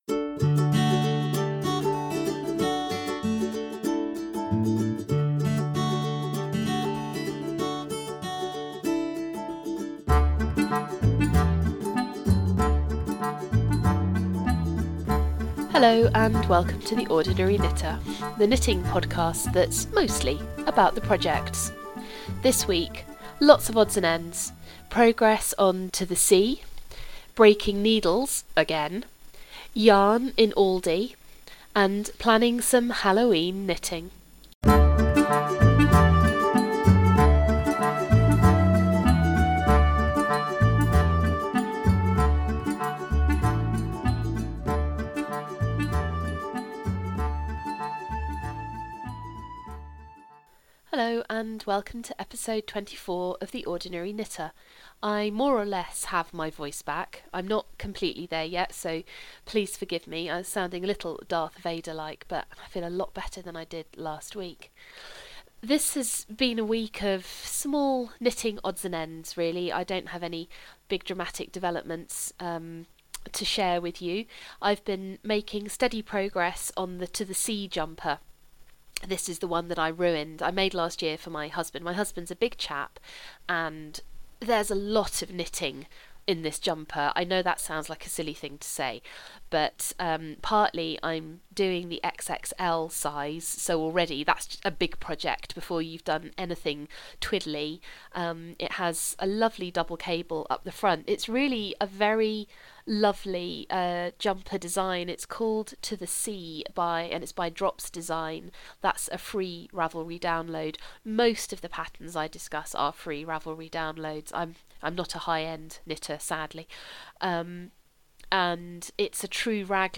Please forgive my coldy voice and the slight jumps in volume. I had to keep stopping to cough for Britain